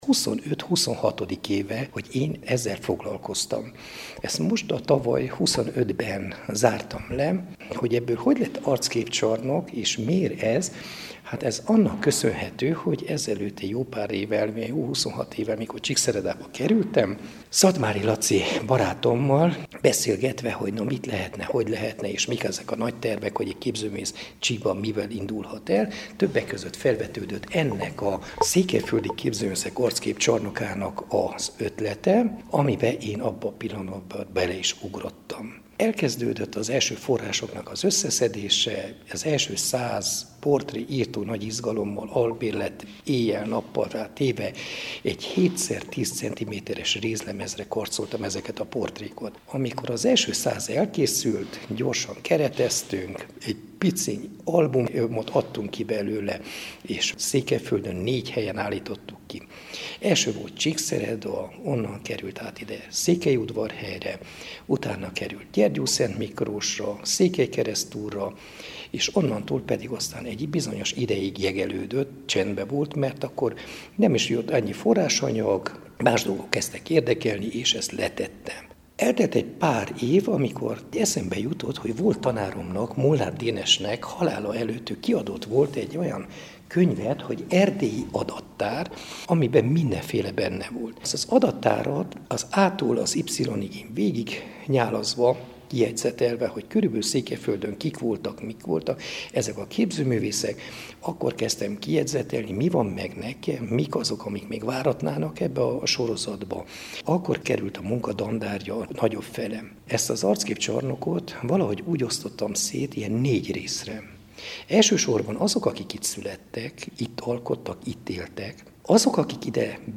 A rézkarc bűvös boszorkánykonyha – Beszélgetés